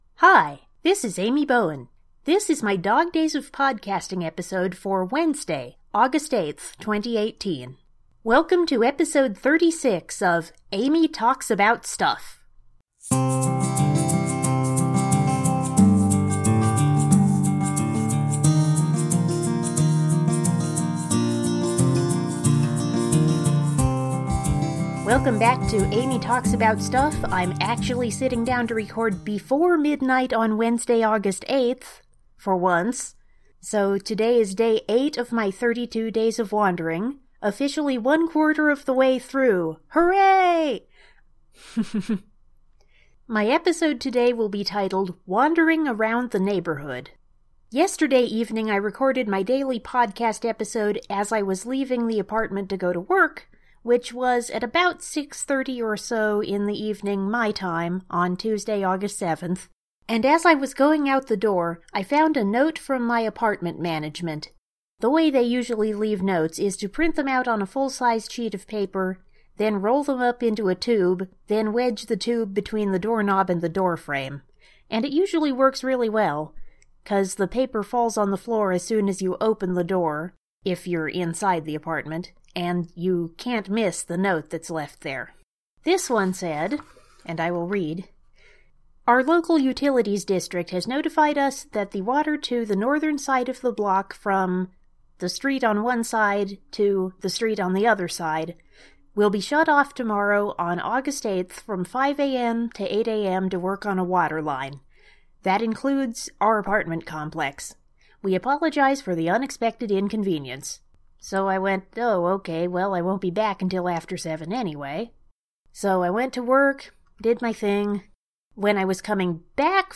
It’s another diary podcasting episode. In this one, I talk about two improvements that I saw being made to my neighborhood today.